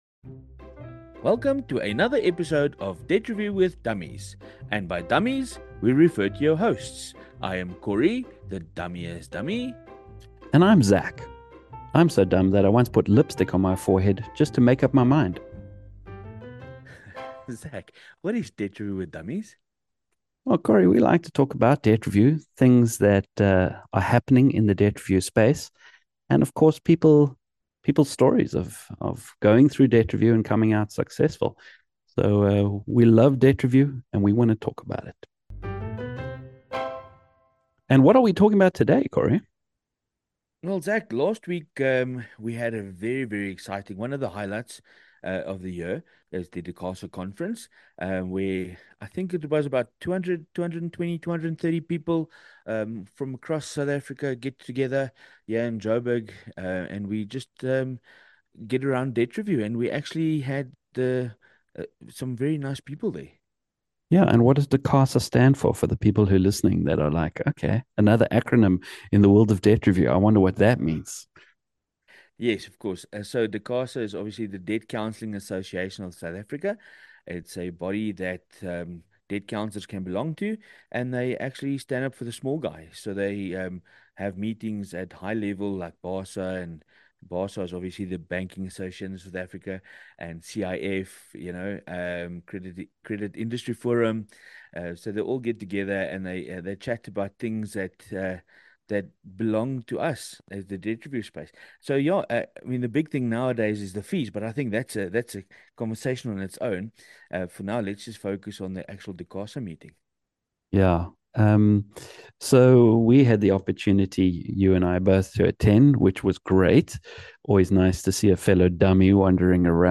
The Dummies chat about the recent Debt Counsellors Association of South Africa annual Conference. We go through the vibe, the lunch options, all the speakers, presentations and our overall impressions as delegates.